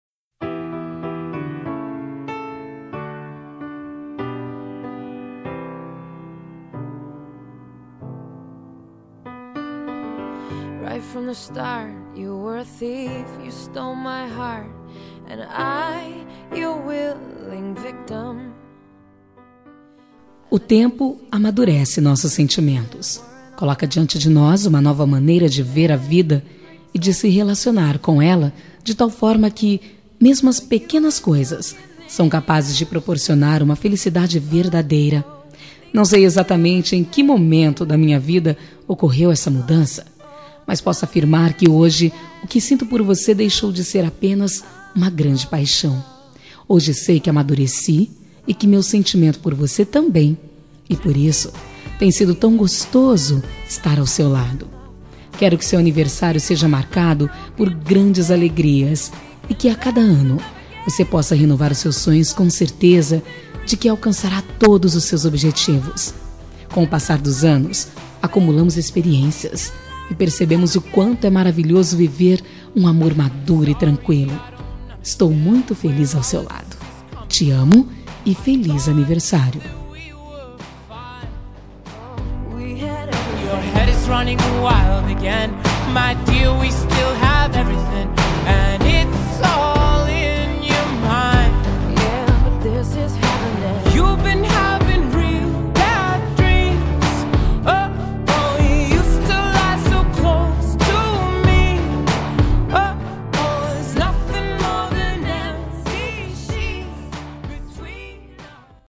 Voz Feminina
Código: 0021 – Música: Just Give Me a Reason – Artista: Pink / Nate Ruess